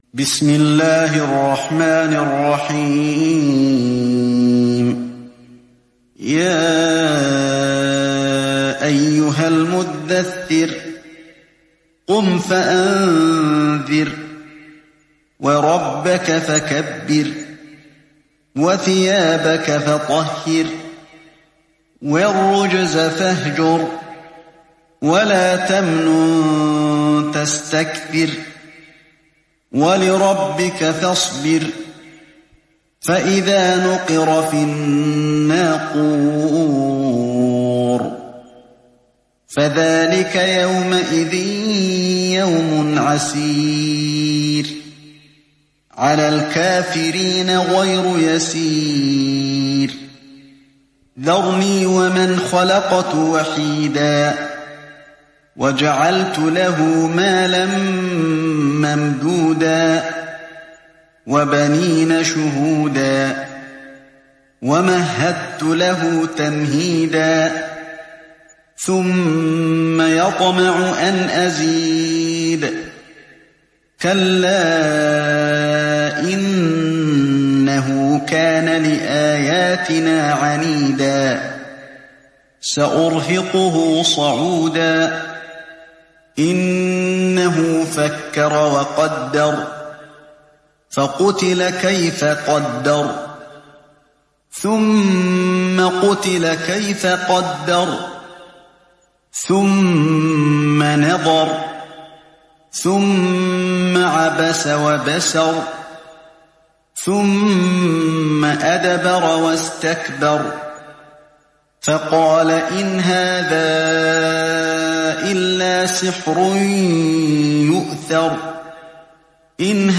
Moratal